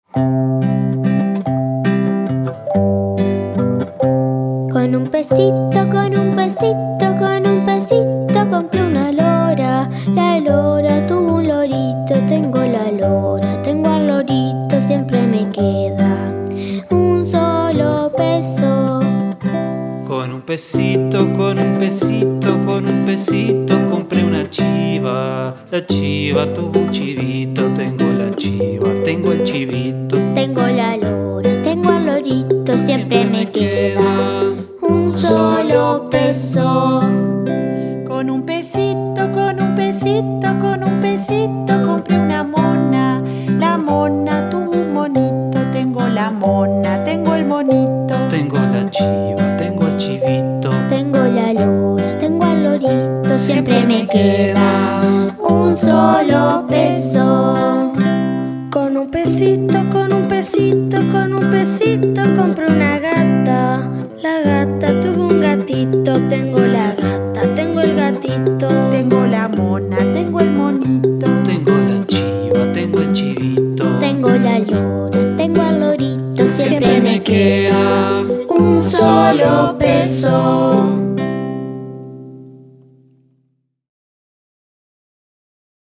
canción acumulativa tradicional venezolana
en compás ternario.